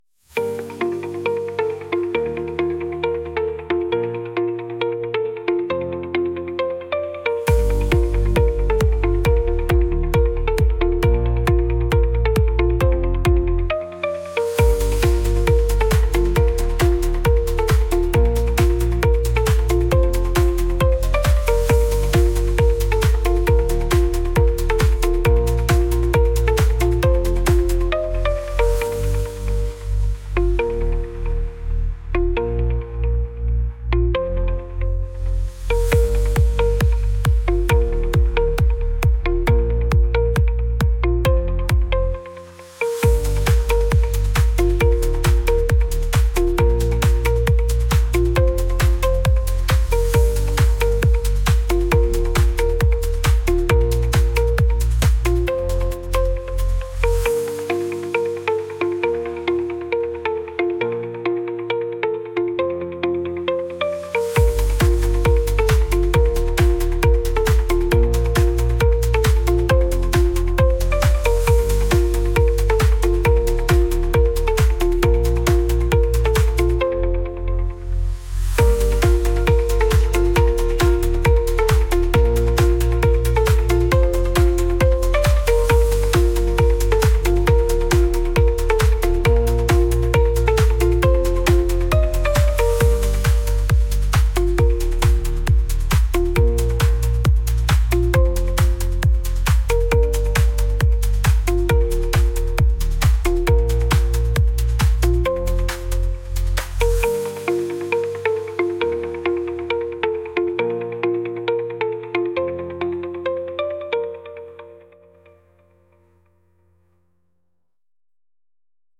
pop | indie | lofi & chill beats